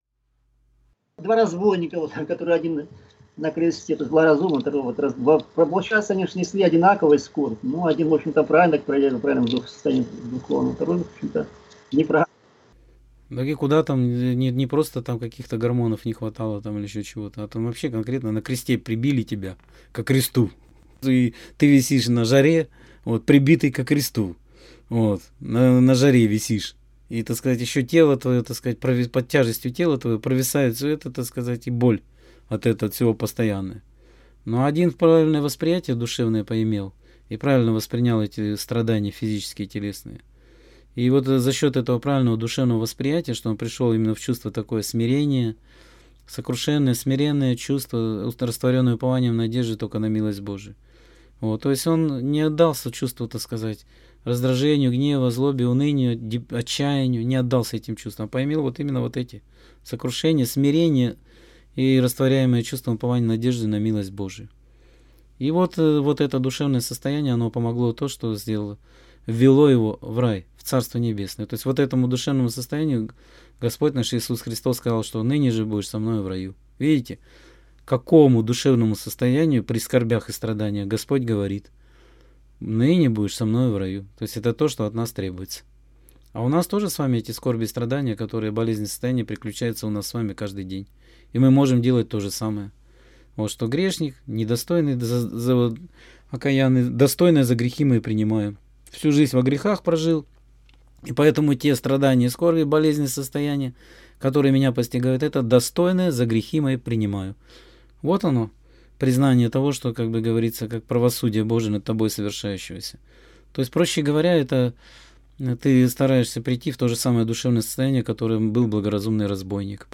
Скайп-беседа 28.10.2017